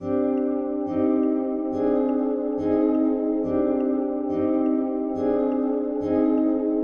标签： 140 bpm Trap Loops Flute Loops 1.15 MB wav Key : Unknown
声道立体声